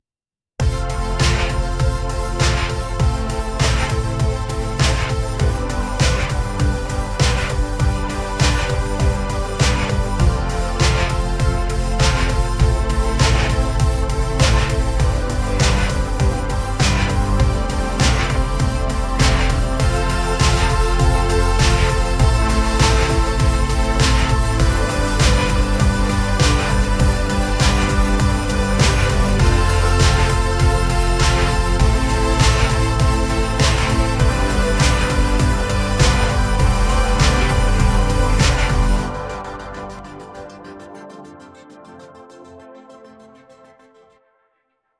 街の一角にある小さなクラブでは、まだ日が昇る前の深夜、シンセポップのリズムが空気を震わせている。